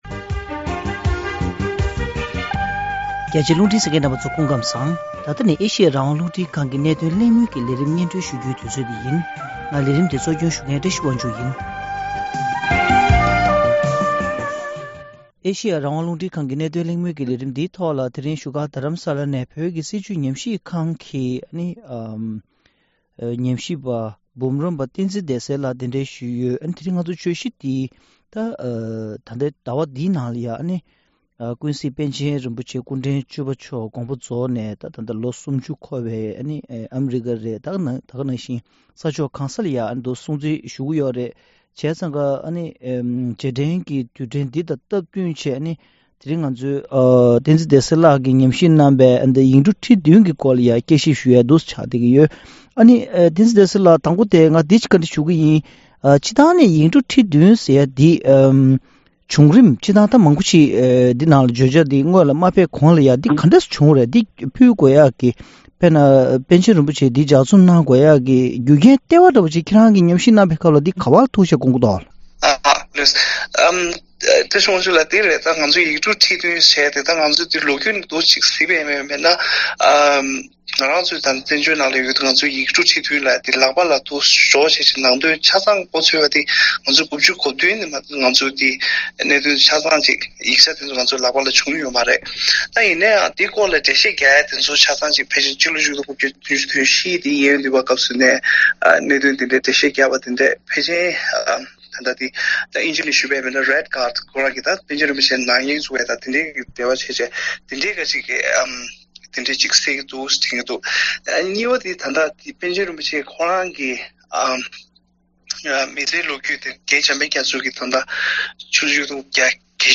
༄༅། །གནད་དོན་གླེང་མོལ་གྱི་ལས་རིམ་འདིའི་ནང་།